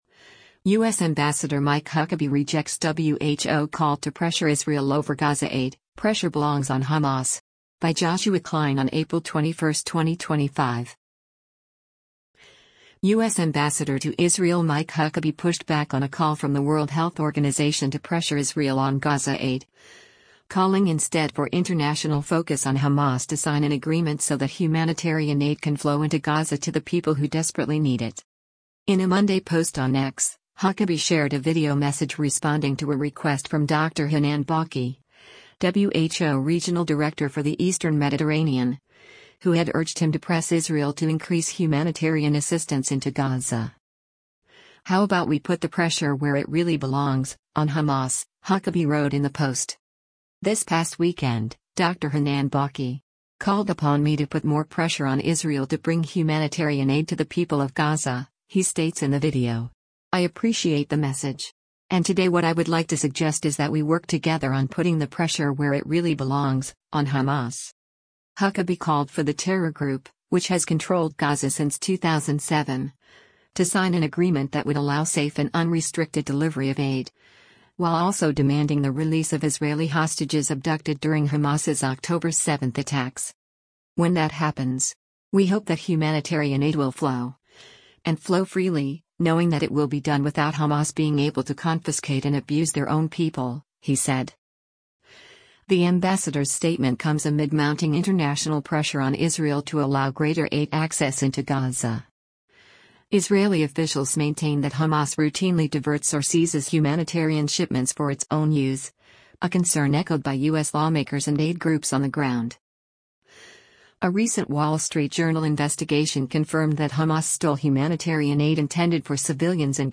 In a Monday post on X, Huckabee shared a video message responding to a request from Dr. Hanan Balkhy, WHO Regional Director for the Eastern Mediterranean, who had urged him to press Israel to increase humanitarian assistance into Gaza.